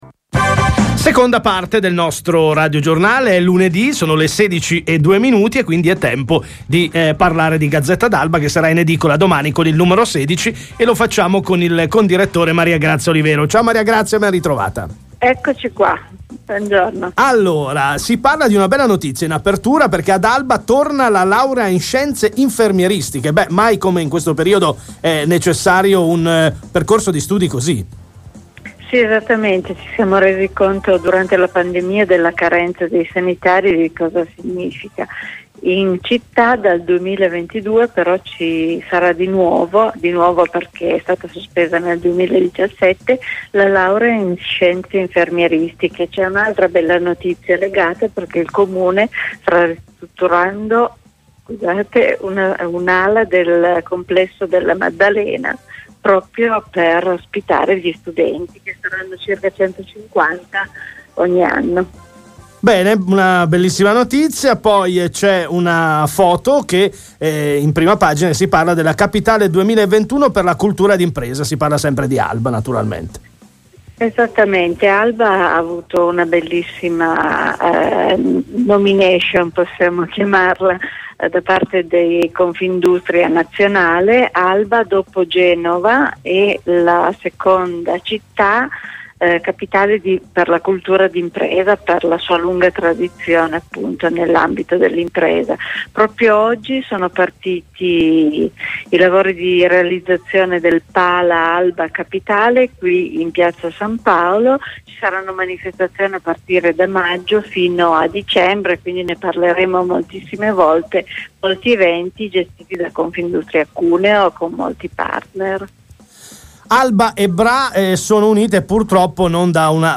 NUOVO NUMERO Ogni lunedì Gazzetta d’Alba ha spazio sulle frequenza di Radio Vallebelbo per presentare il nuovo numero.